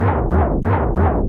Barrel rolling.
Barrel_(rolling).oga.mp3